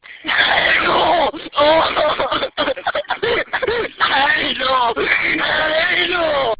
Play, download and share ANAL SCREAM original sound button!!!!
anal-scream.mp3